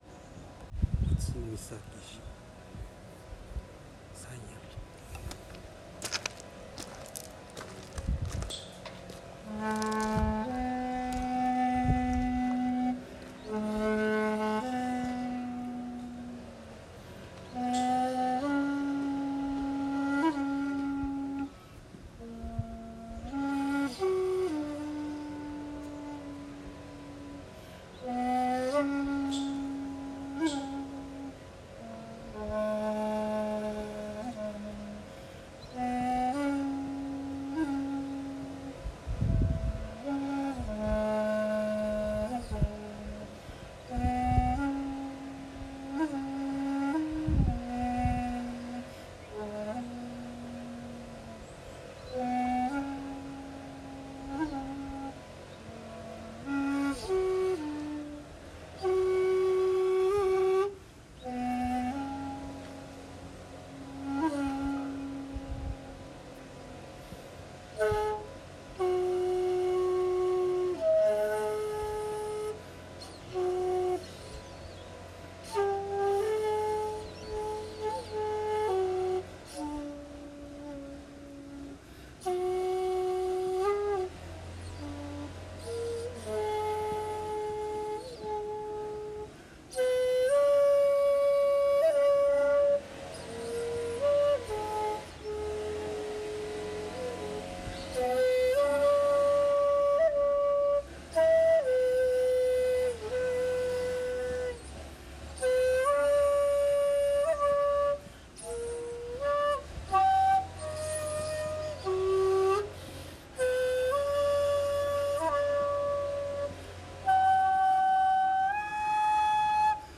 今日唯一の札所ということなので、ゆっくりと尺八を吹奏しました。
（写真⑤：最御崎寺で尺八）
◆◆　（尺八音源：最御崎寺「山谷」）